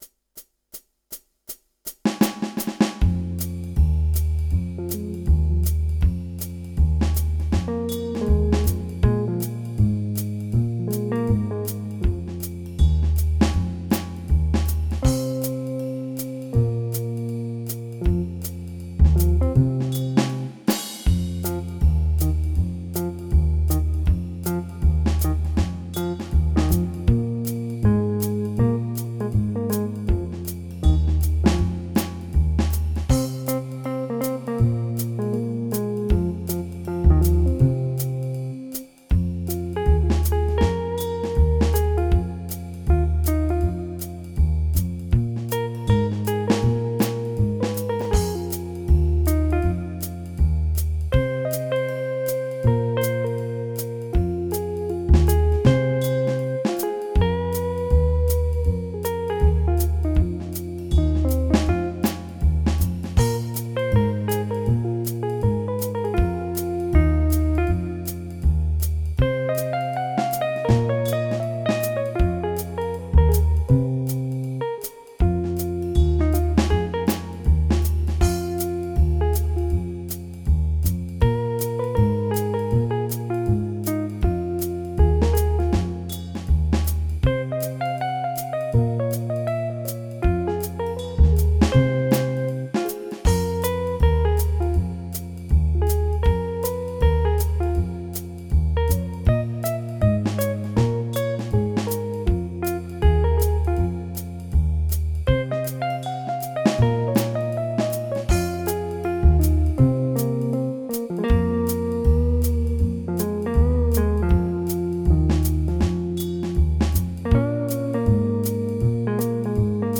sintonía